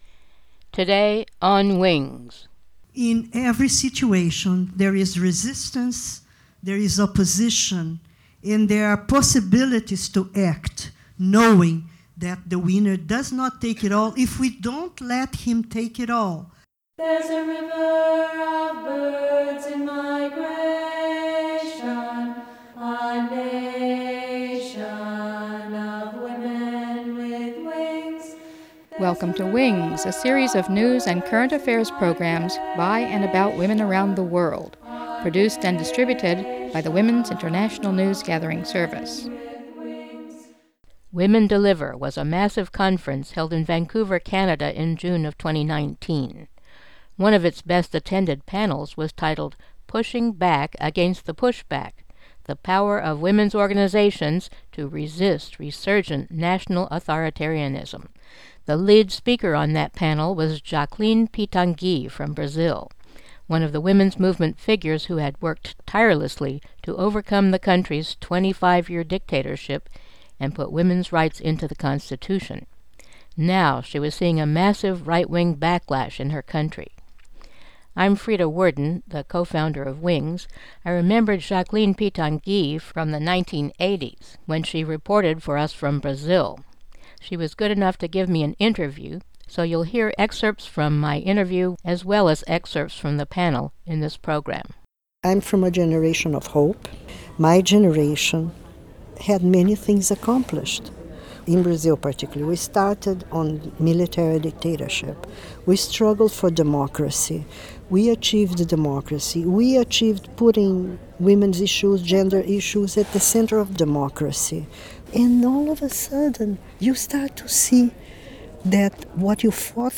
on a panel at Women Deliver 2019